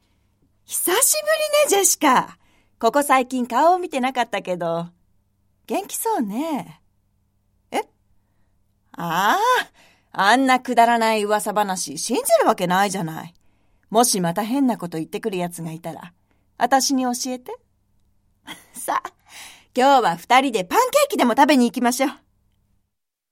ボイスサンプル
セリフ2